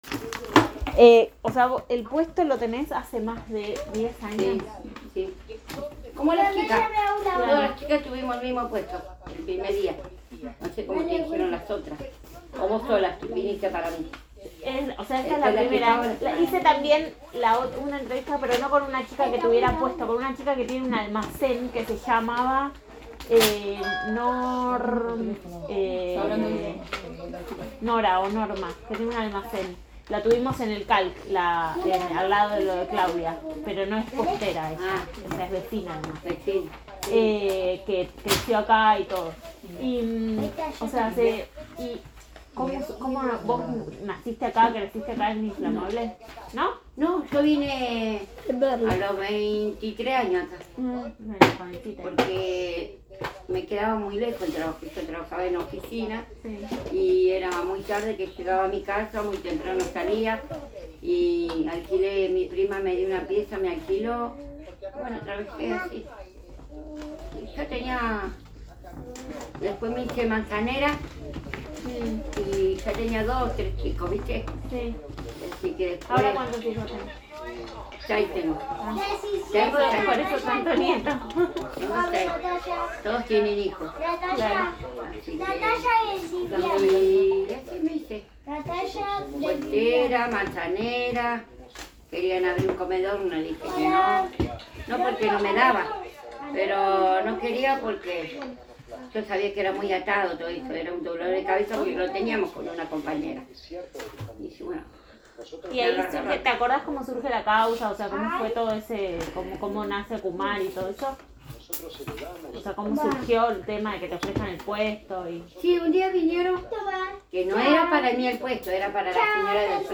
1 grabación sonora en soporte magnético